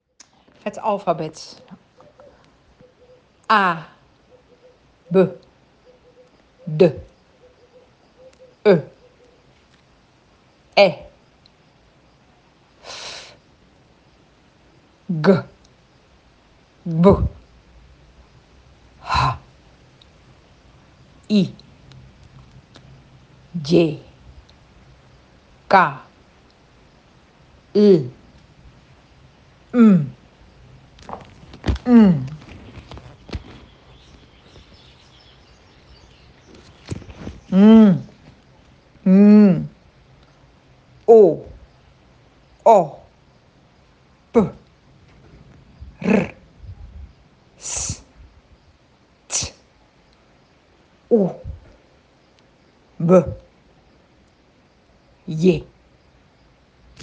Uitspraak Mogofin alfabet
Uitspraak-Mogofin-alfabet.ogg